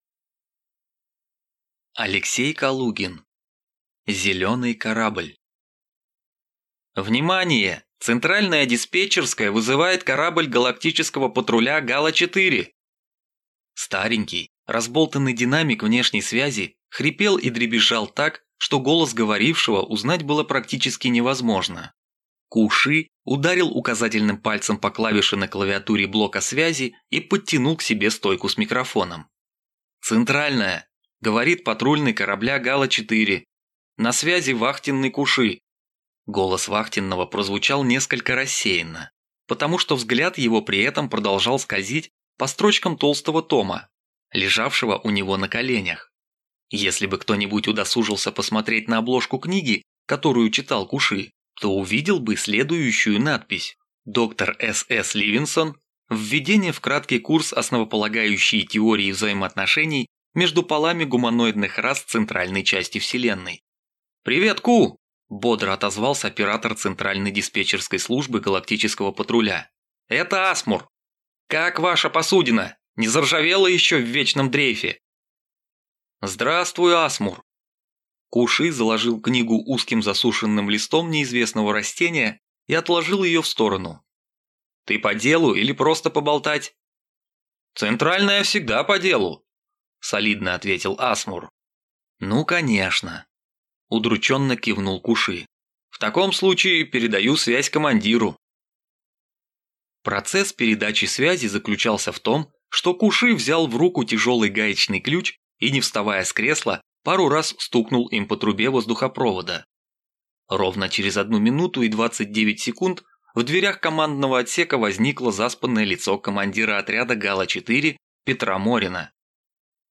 Аудиокнига Зеленый корабль | Библиотека аудиокниг